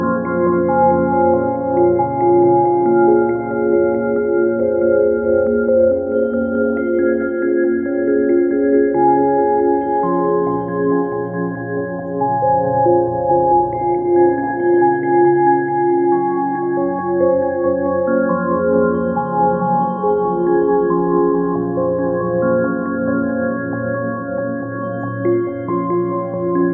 梦幻般的
描述：一个带有混响和延迟的lo fi琶音循环，
Tag: 琶音 低保真 幻想